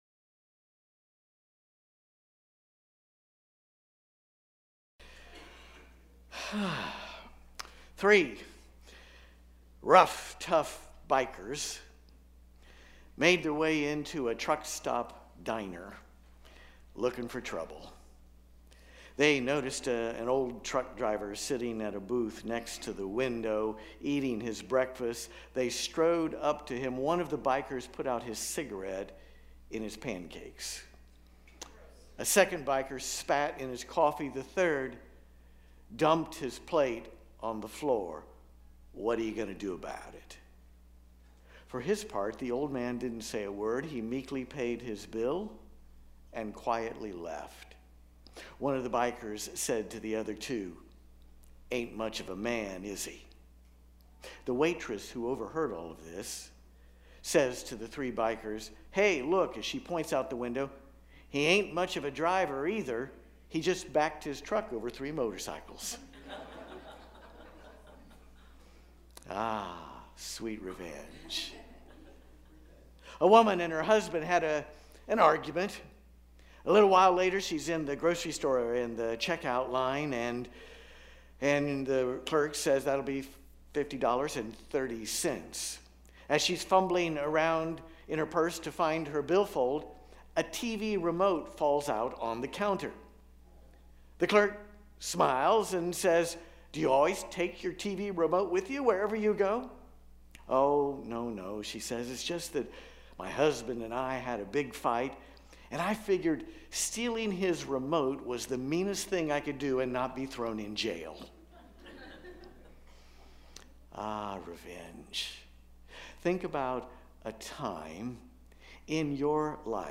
This Sunday we will celebrate Palm Sunday and also wrap up our sermon series on the life of Joseph.